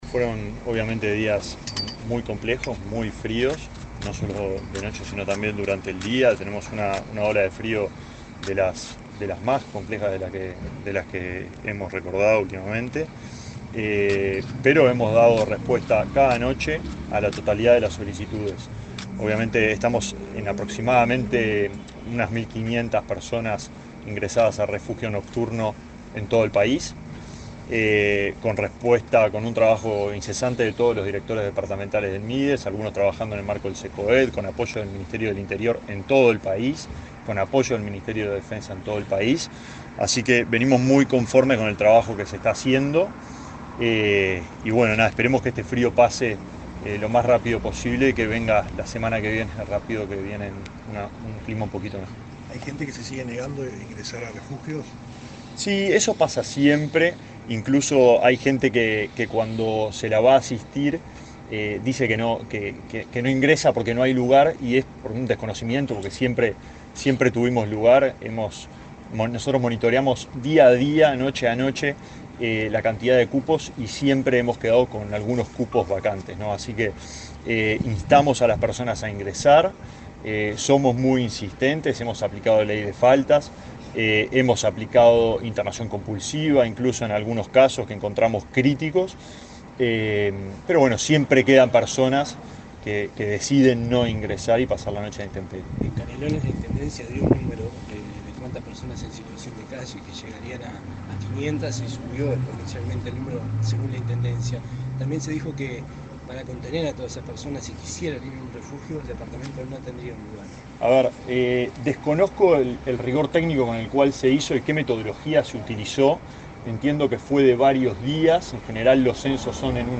El ministro de Desarrollo Social, Alejandro Sciarra, dialogó con la prensa en Las Piedras, Canelones, durante la inauguración de un local de atención